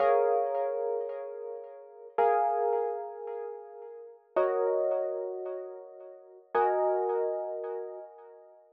03 ElPiano PT3.wav